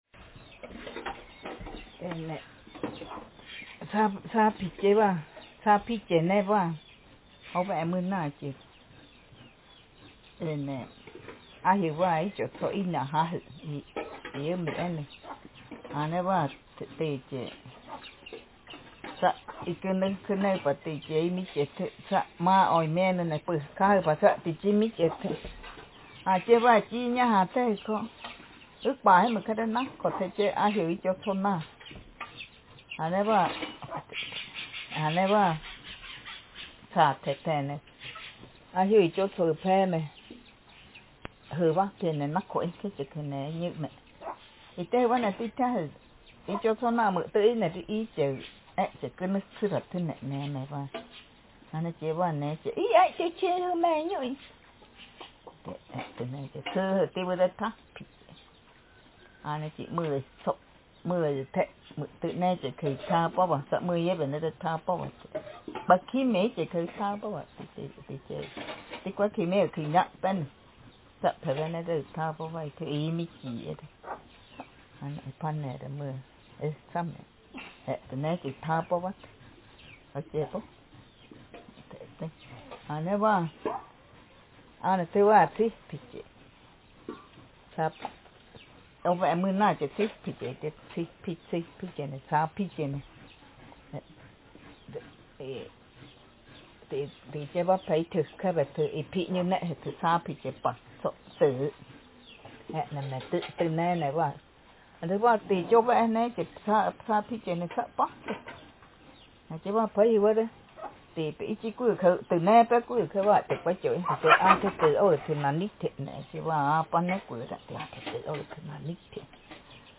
Speaker sexf
Text genretraditional narrative